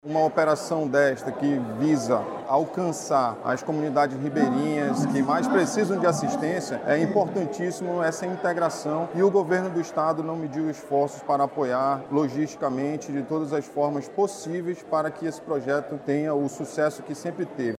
O comandante-geral da Polícia Militar do Amazonas – PMAM, coronel Klinger Paiva, destaca a importância da integração entre as esferas de Poder para que programas como este aconteçam.